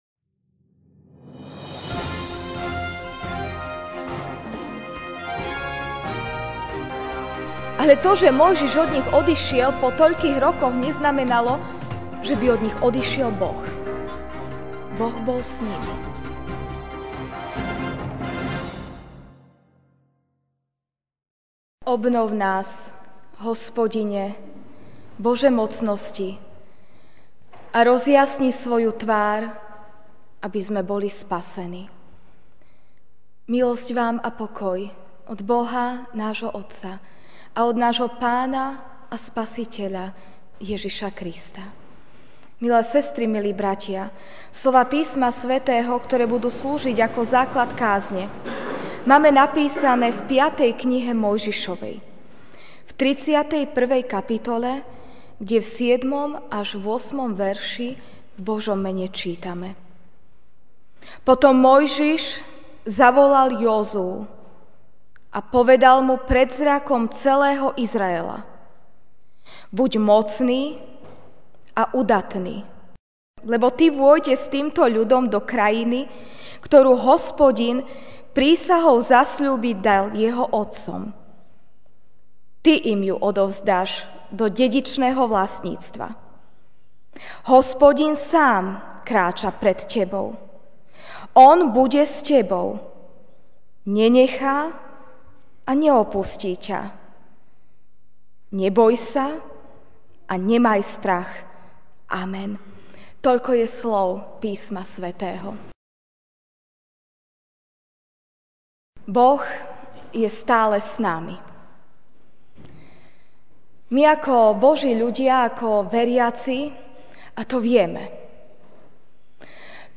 Ranná kázeň: Boh je s tebou (5.